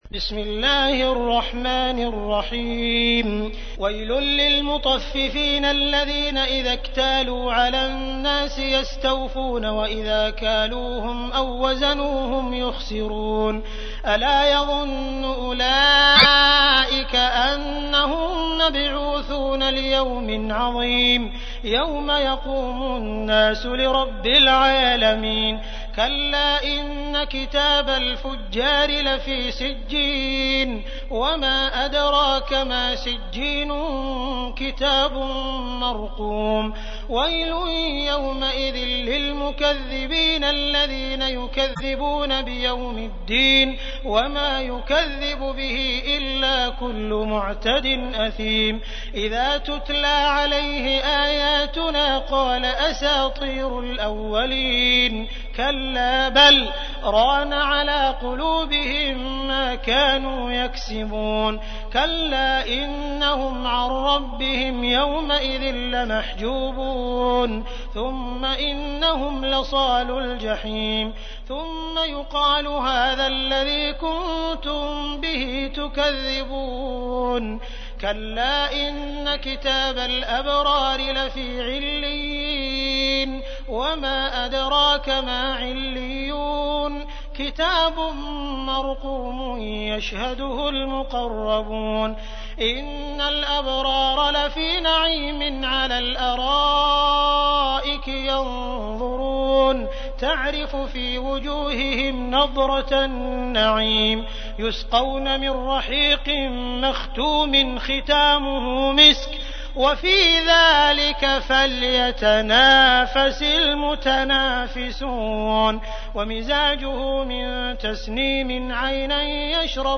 تحميل : 83. سورة المطففين / القارئ عبد الرحمن السديس / القرآن الكريم / موقع يا حسين